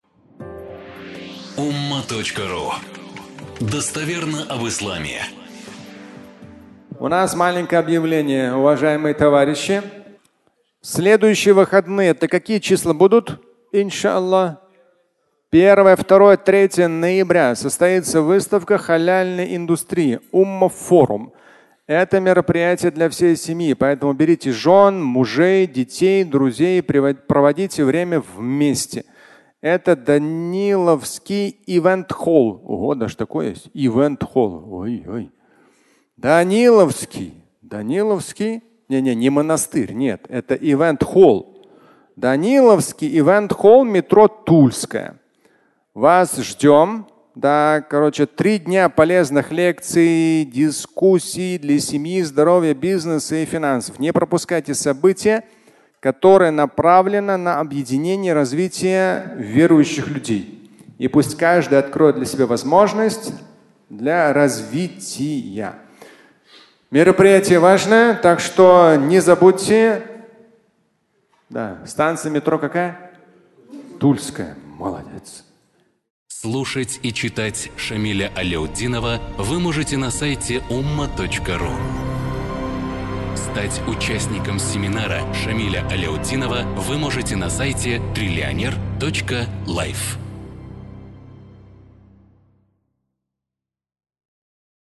Umma Forum (аудиолекция)
Фрагмент пятничной лекции, в котором Шамиль Аляутдинов делает объявление о важном проекте.